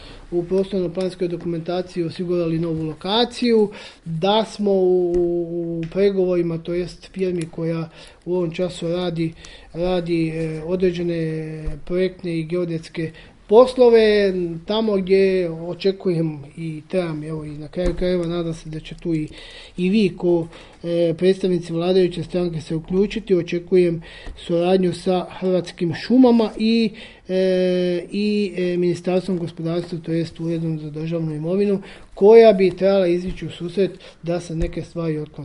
Gradonačelnik Josip Mišković, odgovarajući na pitanje vijećnika pojašnjava